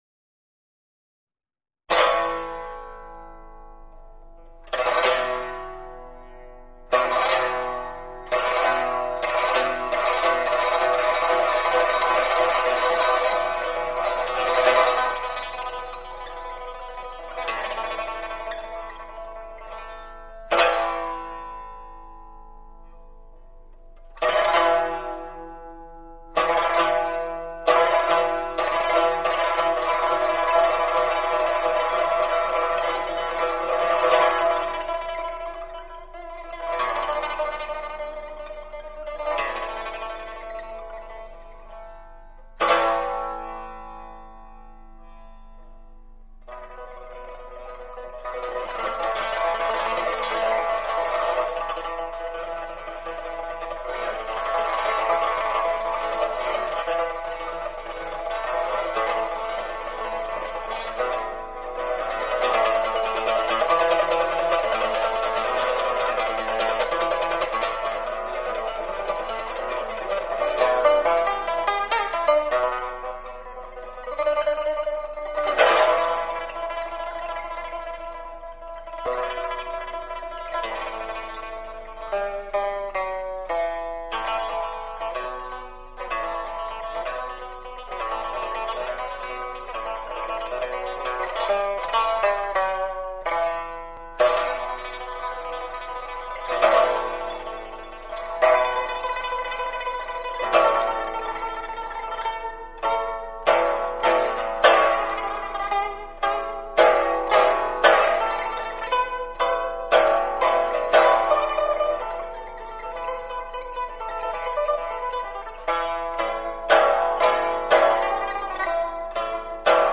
背景音乐二